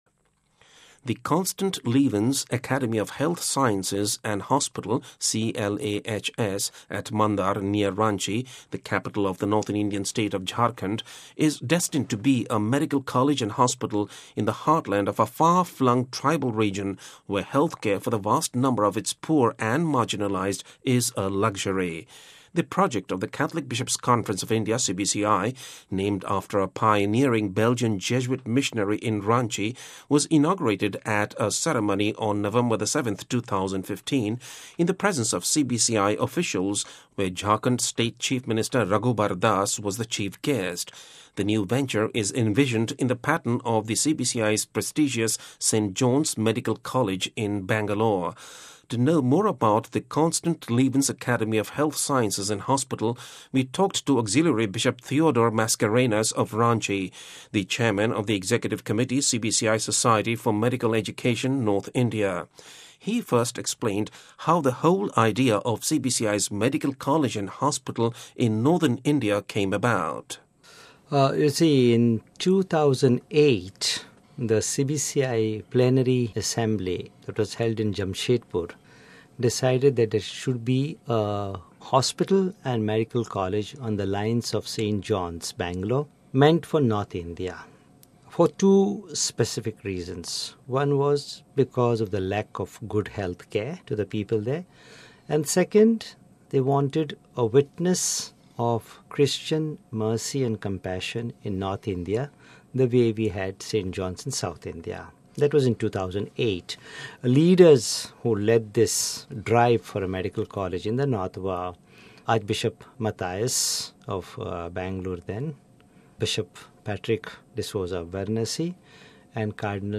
To know more about the Constant Lievens Academy of Health Sciences and Hospital, we talked to Auxiliary Bishop Theodore Mascarenhas of Ranchi, the Chairman of the Executive Committee CBCI Society for Medical Education – North India. He first explained how the whole idea of CBCI’s medical college and hospital in north India came about.